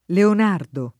leon#rdo] pers. m. — sim. i cogn.